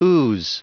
Prononciation du mot ooze en anglais (fichier audio)
ooze.wav